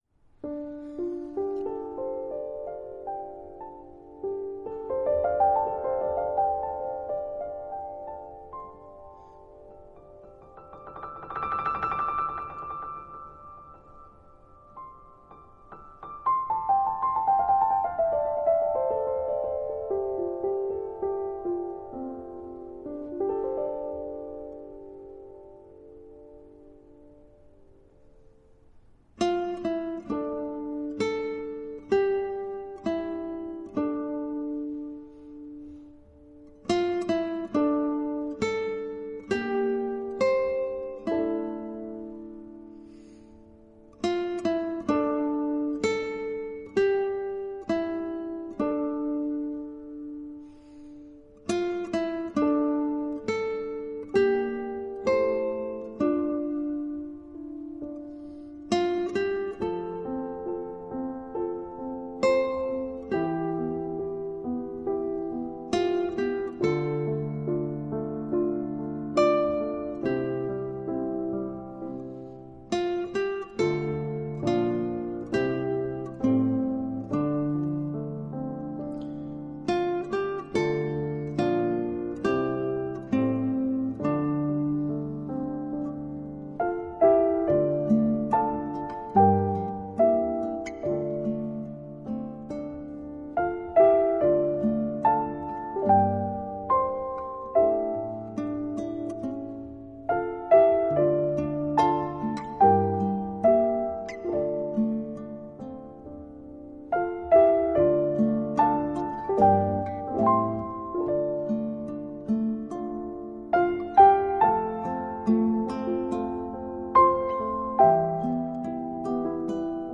引導進入幸福感的寧靜世界
一塵不染的音符，洗滌我們的心靈
本系列所有音樂，都在日本「八之岳山麓」製作完成。